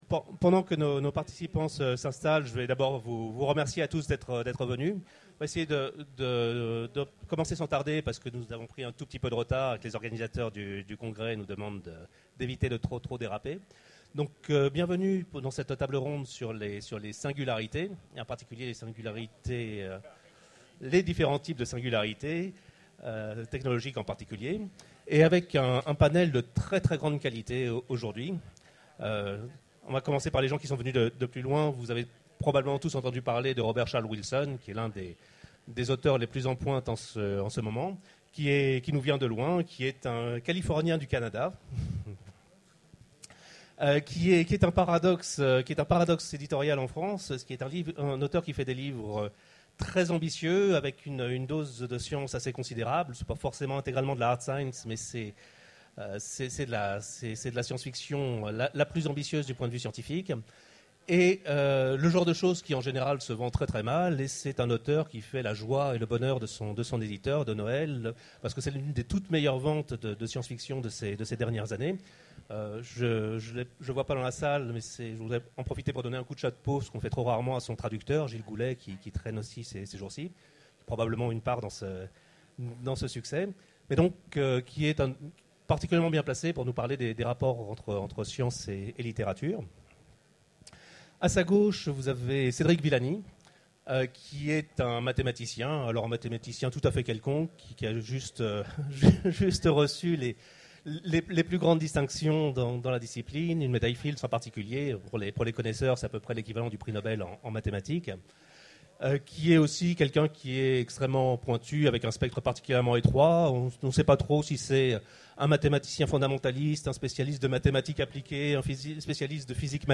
Utopiales 12 : Conférence La singularité (technologique)